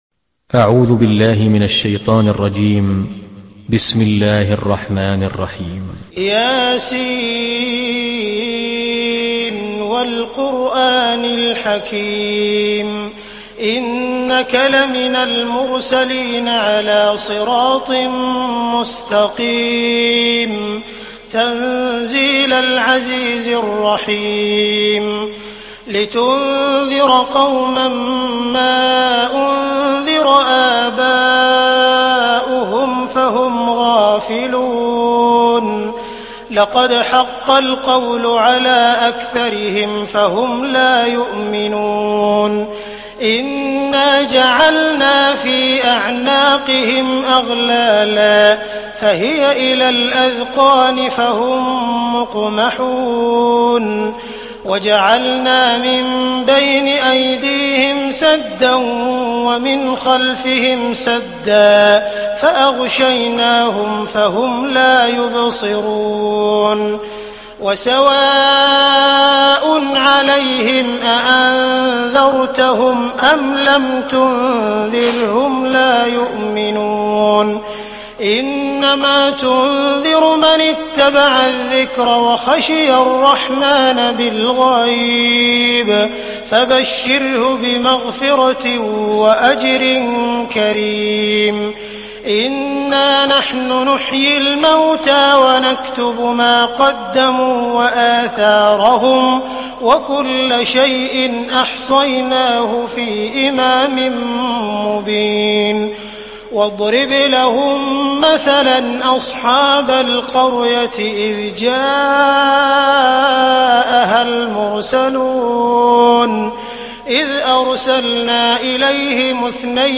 সূরা ইয়াসীন ডাউনলোড mp3 Abdul Rahman Al Sudais উপন্যাস Hafs থেকে Asim, ডাউনলোড করুন এবং কুরআন শুনুন mp3 সম্পূর্ণ সরাসরি লিঙ্ক